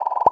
Special Pop (2).wav